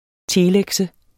Udtale [ ˈteːˌlεgsə ]